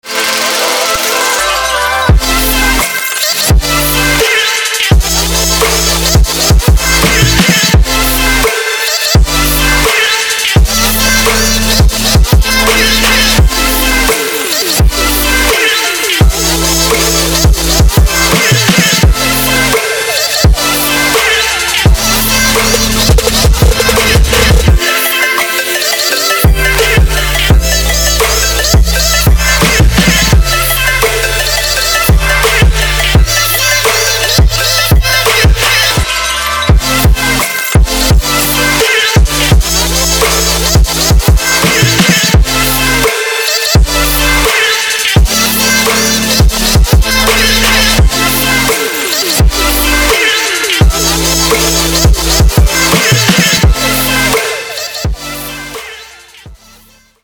• Качество: 192, Stereo
громкие
Electronic
Trap
забавный голос
future bass
Style: trap, future bass.